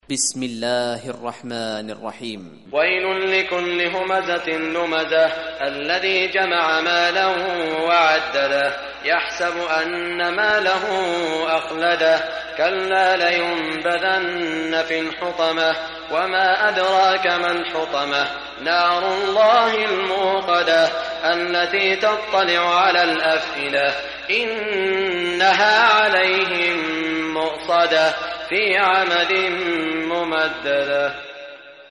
Surah Al-Humazah Recitation by Sheikh Shuraim
Surah Al-Humazah, listen or play online mp3 tilawat / recitation in the beautiful voice of Sheikh Saud Al Shuraim.